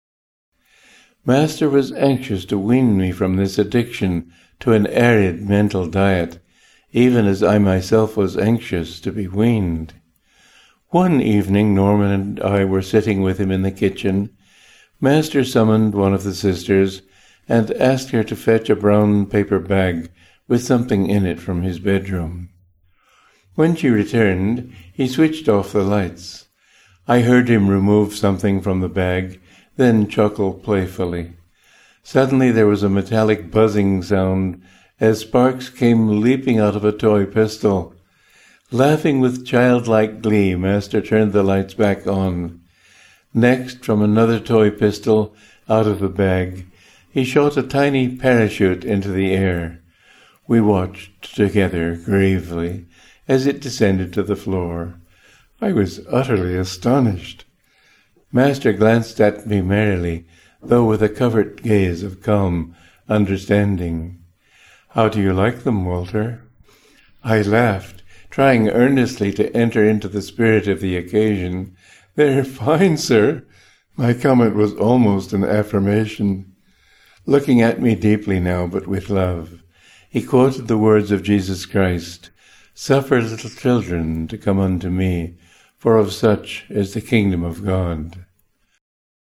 Yogananda continued to transform his intellectual dryness, gradually turning him into a spiritual “diamond”. Listen to Swami Kriyananda who recounts an episode of this training.